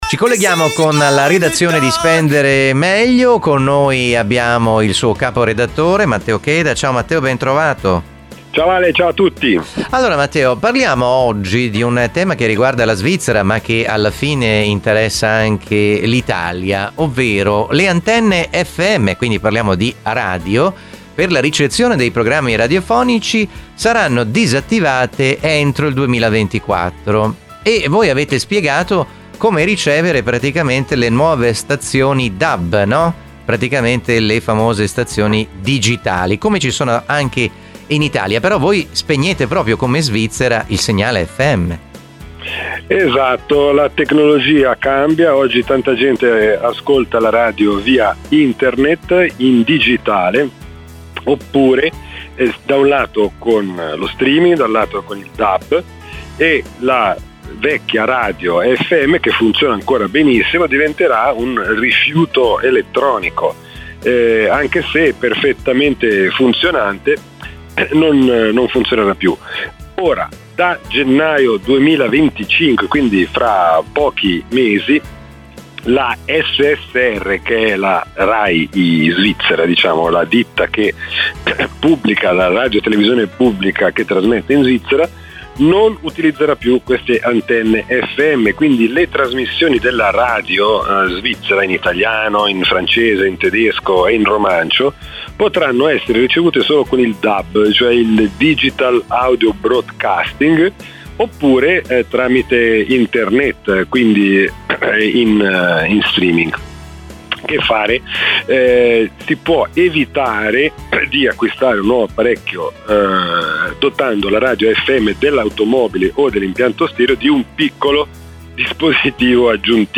Un focus preciso su molti temi in diretta dalla redazione di Bellinzona (Canton Ticino).
Un talk show con ospiti illustri e tanti personaggi, giornalisti, opinionisti ed esperti.